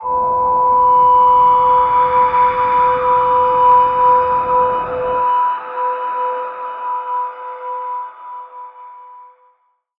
G_Crystal-B5-f.wav